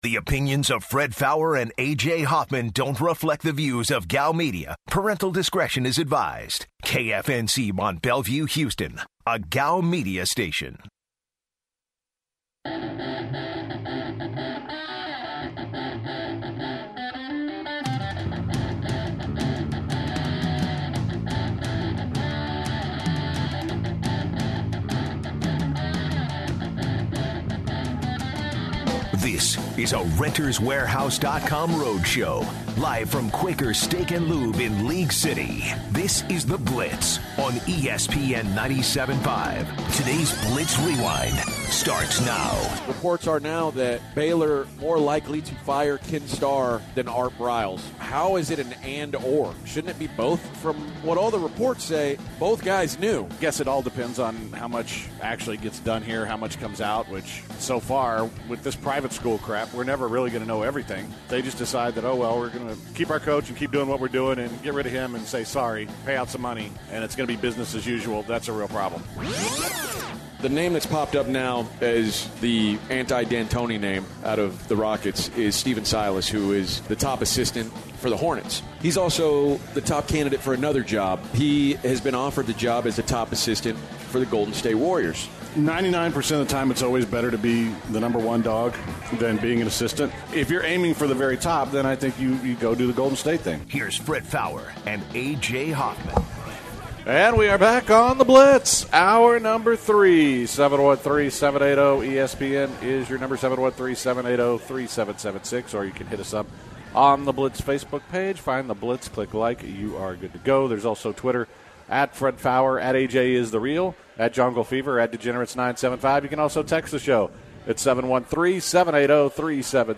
close out The Blitz from Quaker Steak & Lube